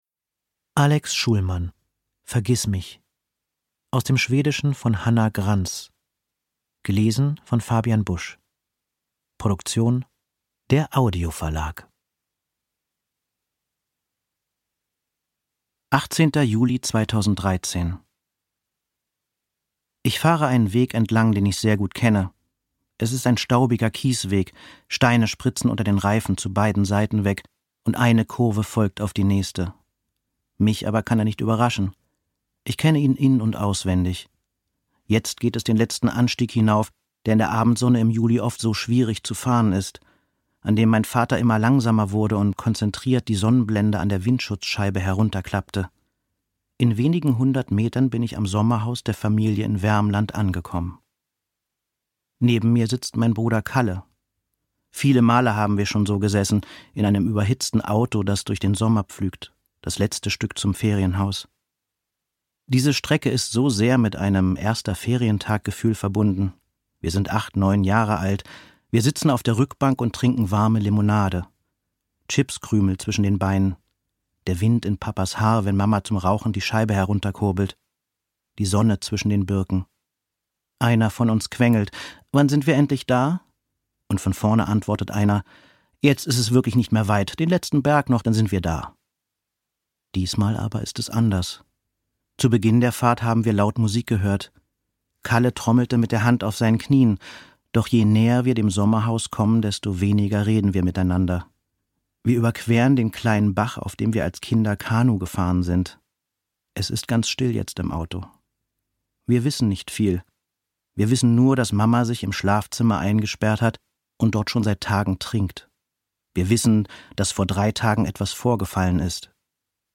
Ungekürzte Lesung mit Fabian Busch (1 mp3-CD)
Fabian Busch (Sprecher)